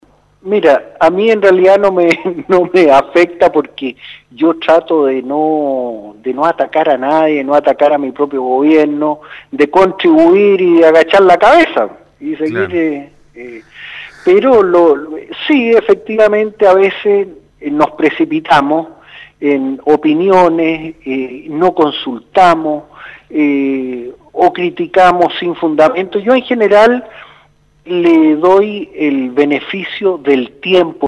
Mientras trabaja en su labor legislativa el Diputado Carlos Ignacio Kuschel, hace un alto para conversar de variados temas con el Programa Haciendo Ciudad de Radio SAGO, uno de ellos es la preocupación que existía en el gobierno por el denominado “fuego amigo”, lo que incluso motivo una reunión entre Chile Vamos y el presidente Sebastián Piñera, para luego dar señales de unidad. KUSCHEL no practica el fuego amigo y señala que trabaja incondicionalmente con el gobierno.